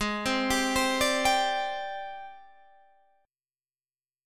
AbM#11 chord